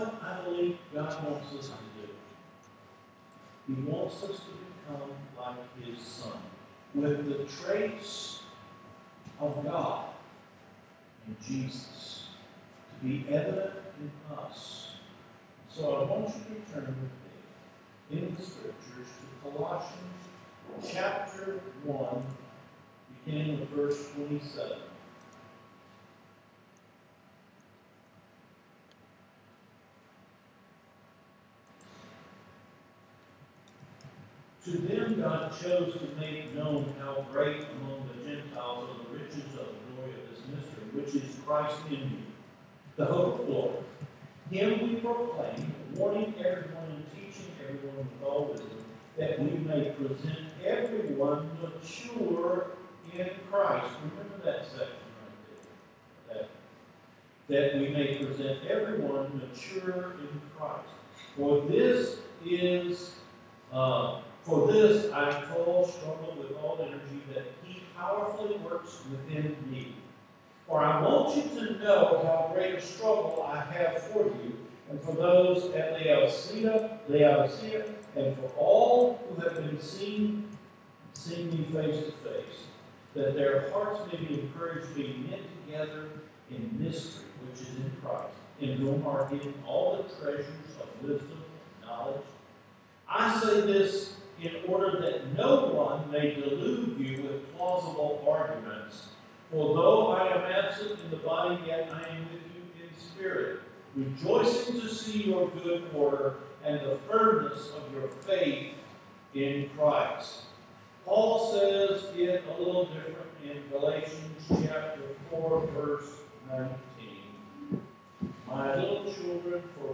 NOVEMBER 7 SERMON – IMAGE BEARERS (TRANSFORMATIONAL GRACE: BECOMING IMAGE BEAERS)